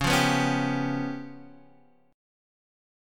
C#7b5 chord {x 4 3 4 2 3} chord